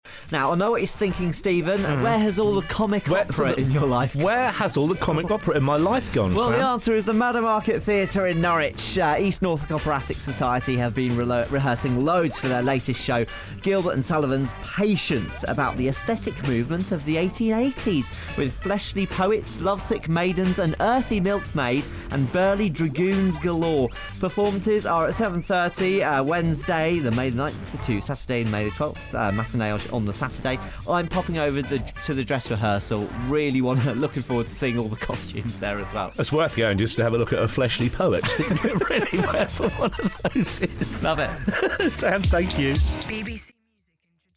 Publicity Trailer broadcast on BBC Radio Norfolk on 30 April 2018